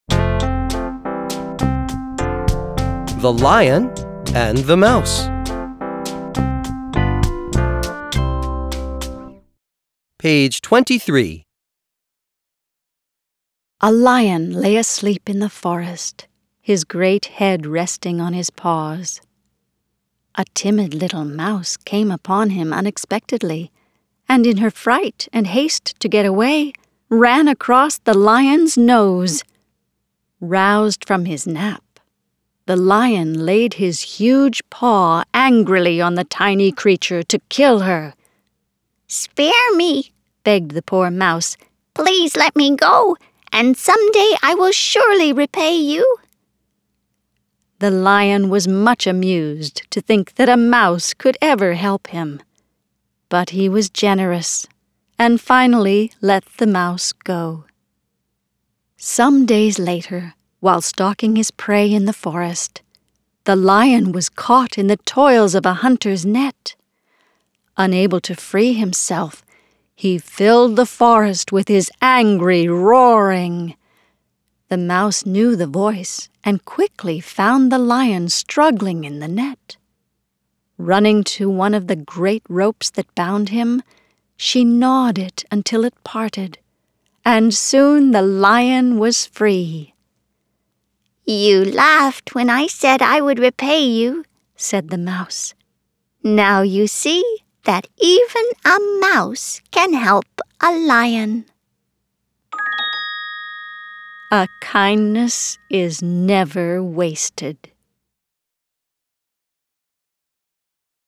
Plus, each beautiful keepsake edition includes an audio CD of the very best stories from the book.